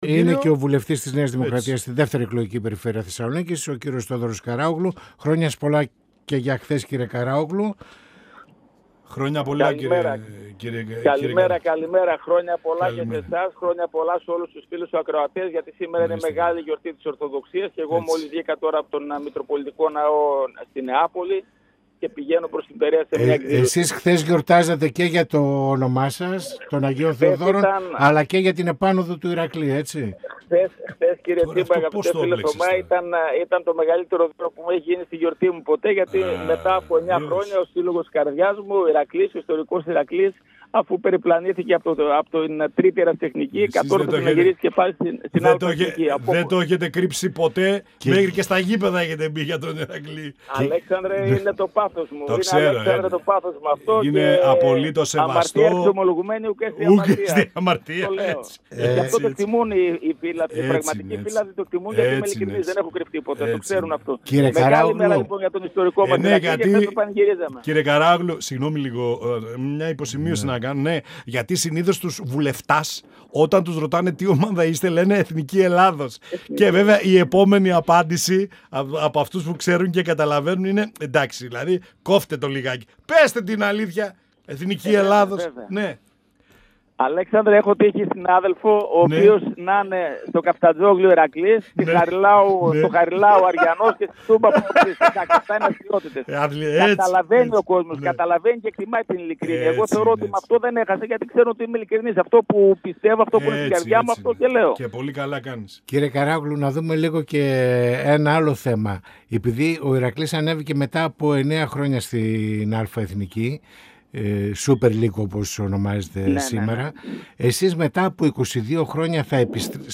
Στην ολοκλήρωση του κύκλου των 22 συνεχόμενων ετών στο Κοινοβούλιο ως βουλευτής της ΝΔ και την πρόθεσή του να κατέλθει στο στίβο της Αυτοδιοίκησης ως υποψήφιος περιφερειάρχης  Κεντρικής Μακεδονίας αναφέρθηκε ο Βουλευτής της ΝΔ Θόδωρος Καράογλου, μιλώντας στην εκπομπή «Πανόραμα Επικαιρότητας»  του 102FM της  ΕΡΤ3.